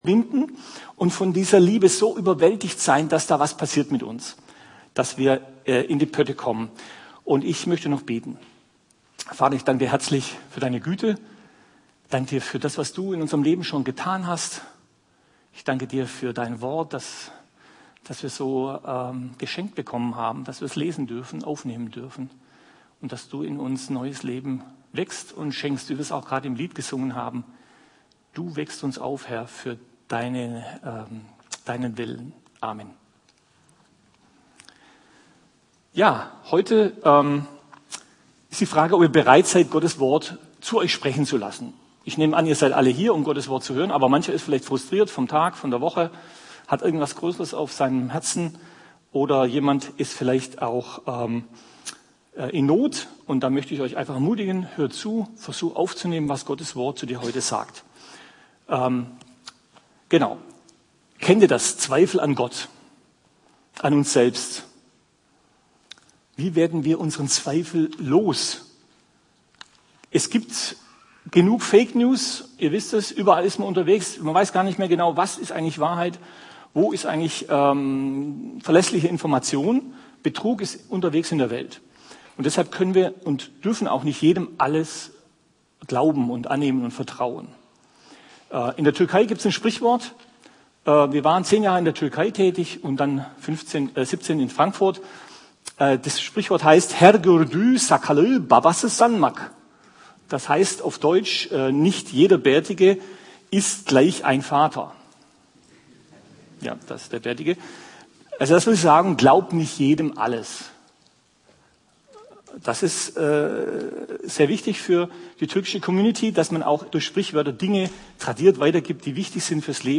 Zweifel überwinden, von der Liebe überwältigt 25. Mai 2025 Predigt Evangelien , Johannes , Neues Testament Mit dem Laden des Videos akzeptieren Sie die Datenschutzerklärung von YouTube.